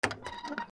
豪邸の玄関扉を開ける 弱 01